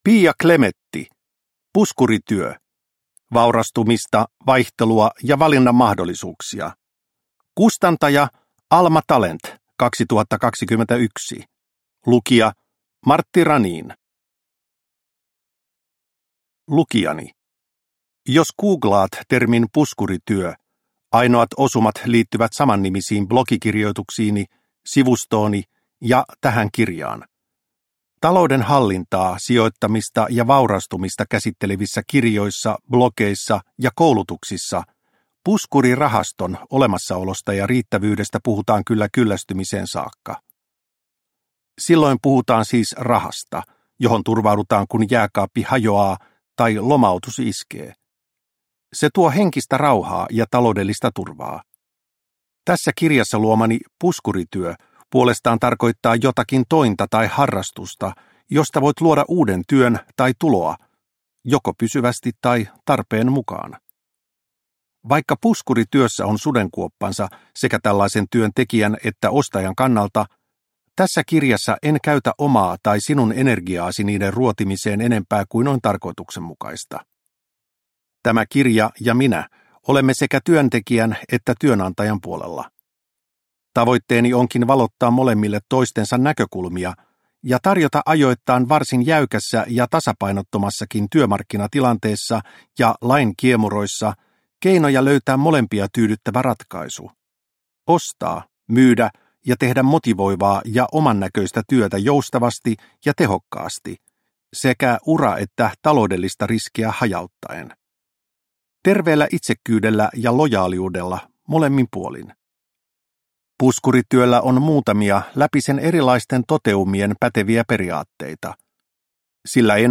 Puskurityö – Ljudbok – Laddas ner